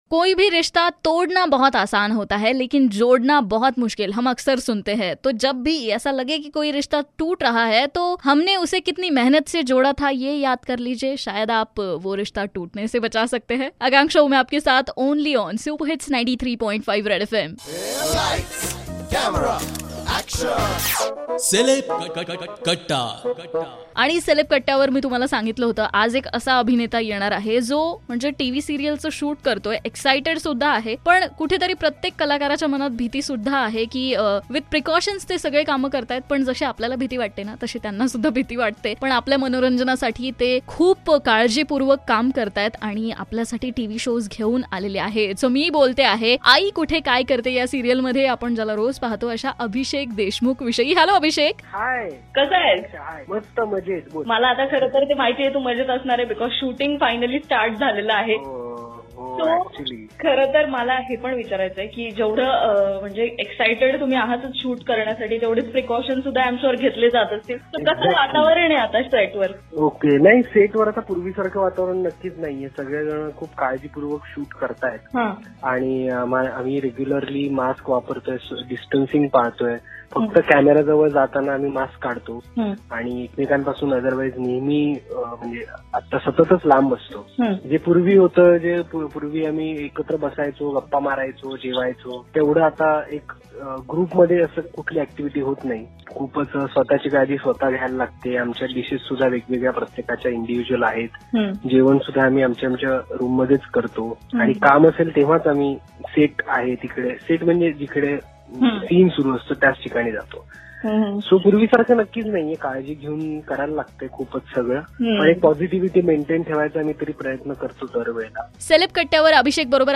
interview
famous marathi actor